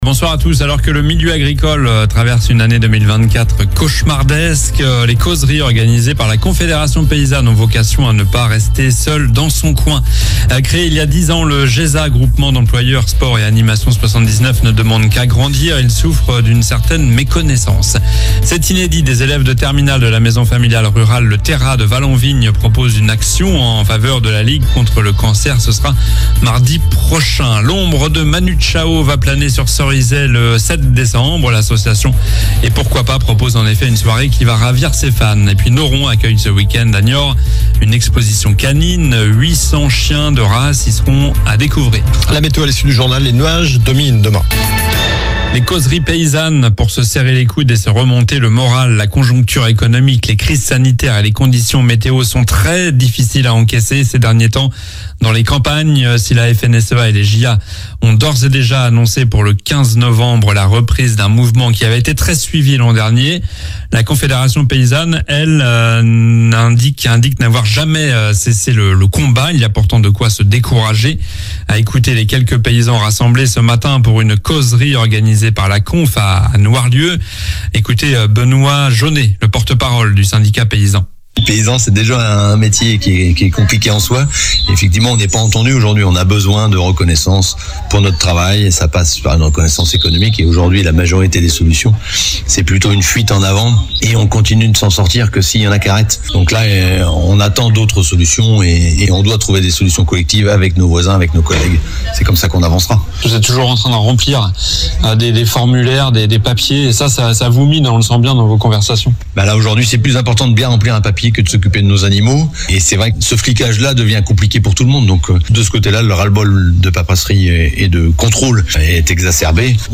Journal du jeudi 24 octobre (soir)